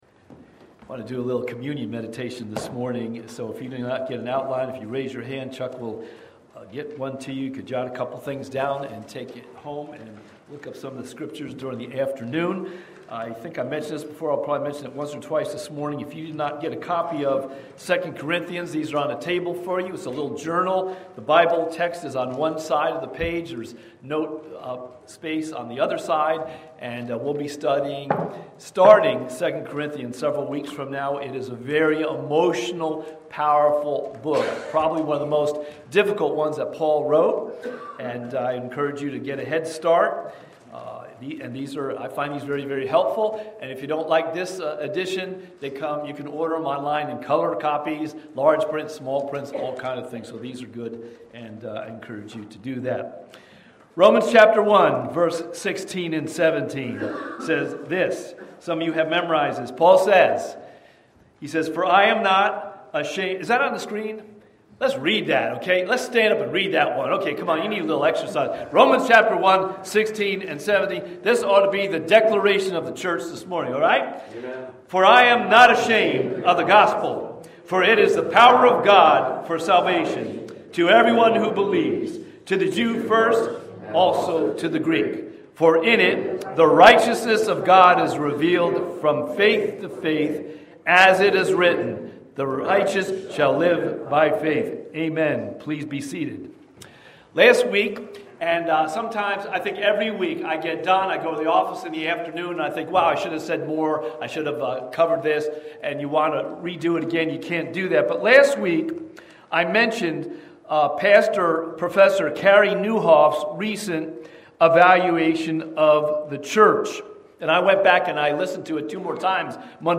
Watch Online Service recorded at 9:45 Sunday morning.